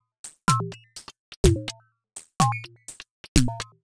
tx_perc_125_ringmodoffit.wav